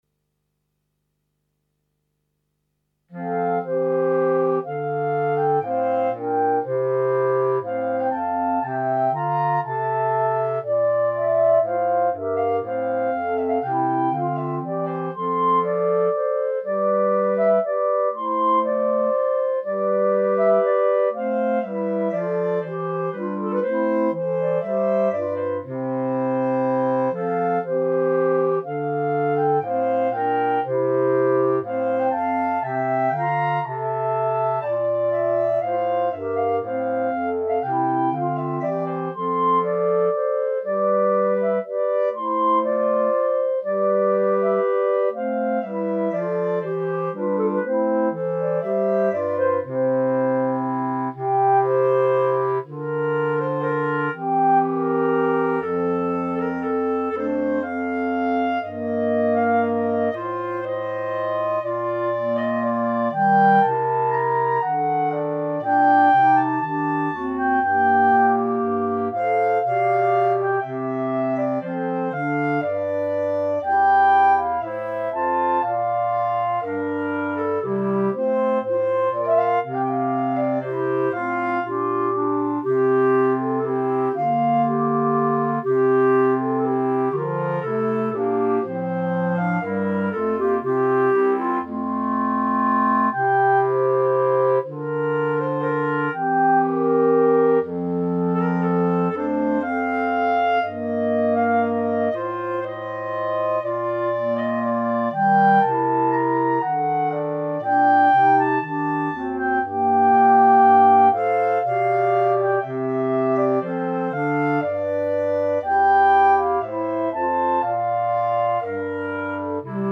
Clarinet Quartet
Instrumentation: 3 Clarinet, Bass Clarinet